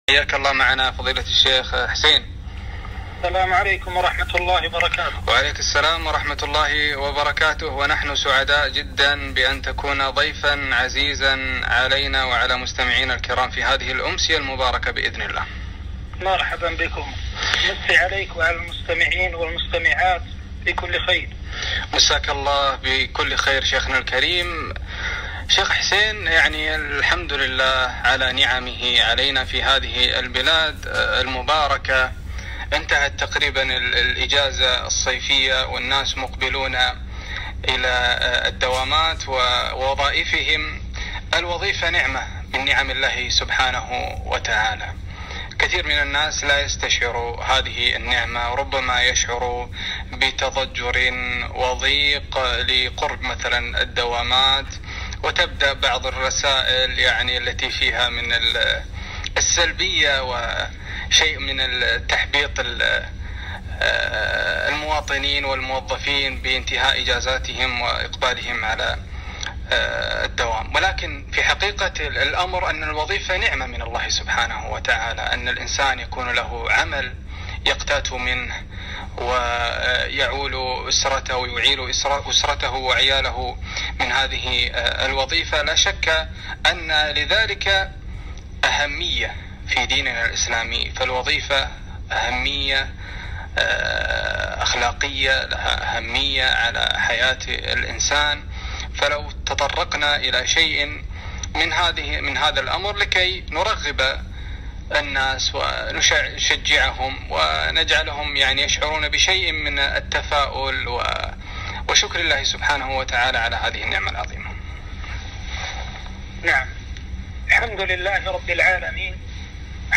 أخلاق الموظف - لقاء إذاعي